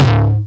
909 Tom Distorted.wav